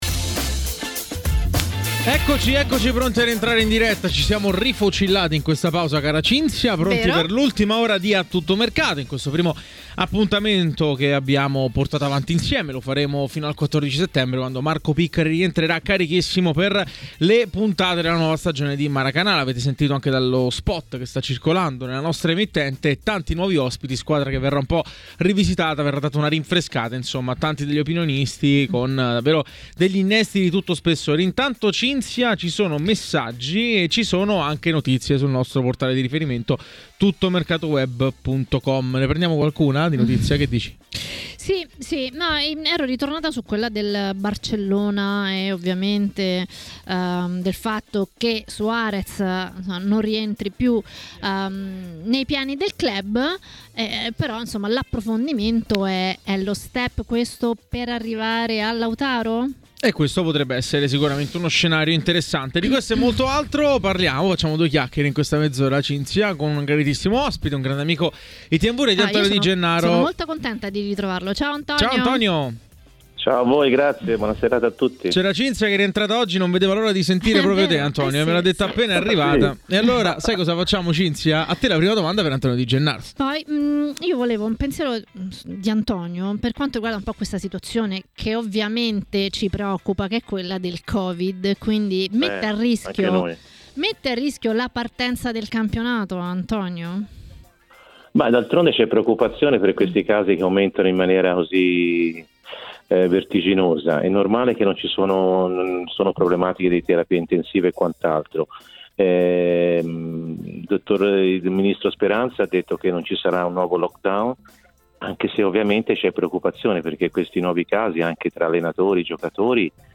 Ai microfoni di TMW Radio ha parlato l’ex giocatore Antonio Di Gennaro. Di seguito le sue parole riguardo il Milan, l'Inter e la Juventus: